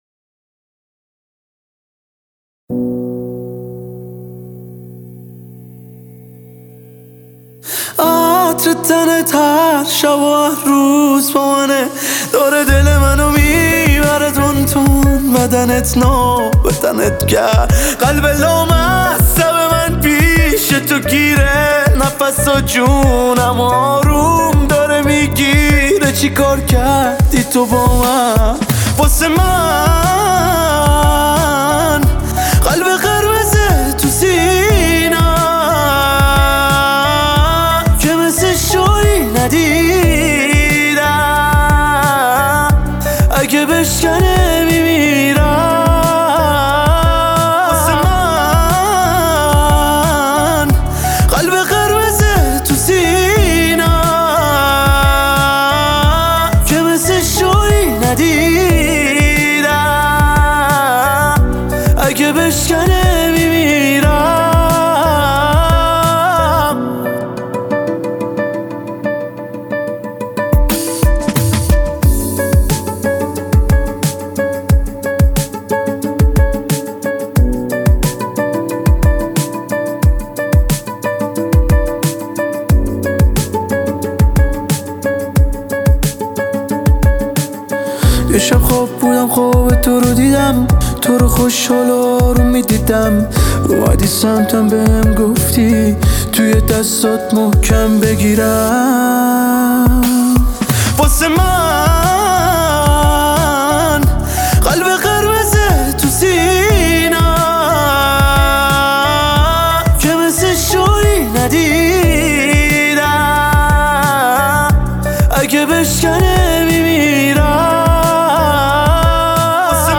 یک خواننده پاپ